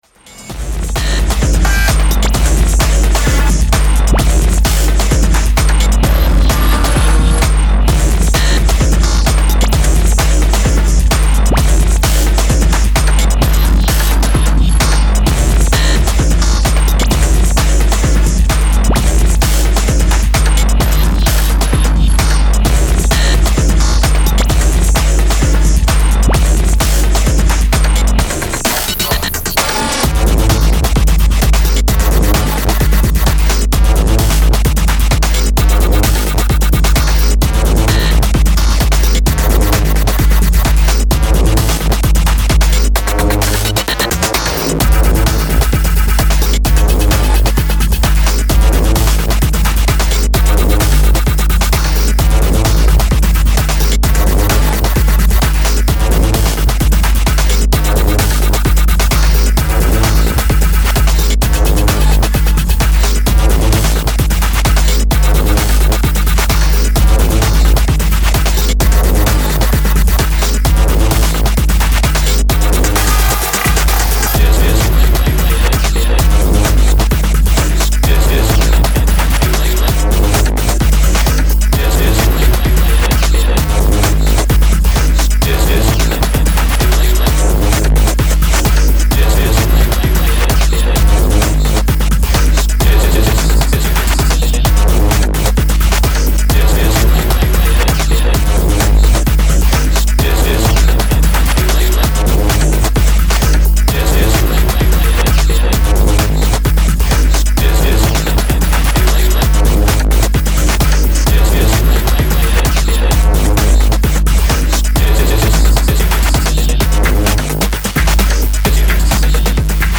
Style: Brighton Techno / Electro